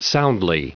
Prononciation du mot soundly en anglais (fichier audio)
Prononciation du mot : soundly